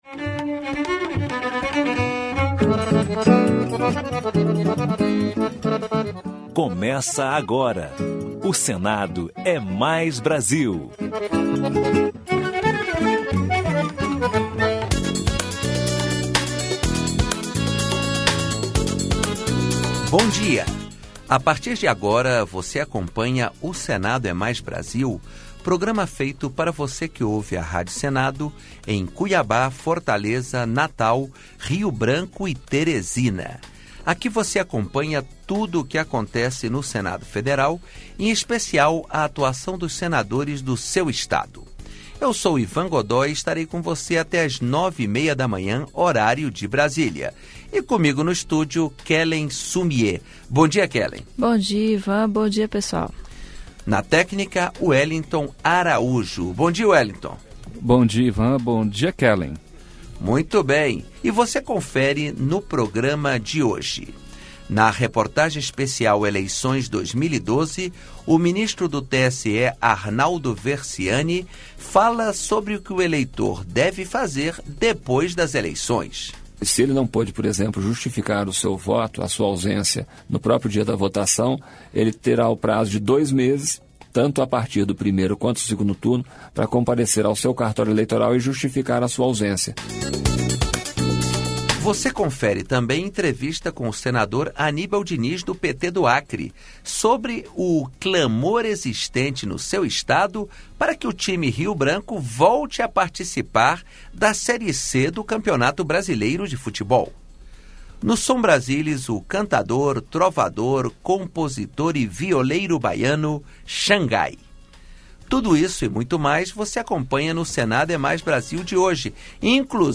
Entrevista Especial: Senador Aníbal Diniz (PT-AC) fala sobre o clamor para que o Rio Branco volte a disputar a Série C do Brasileirão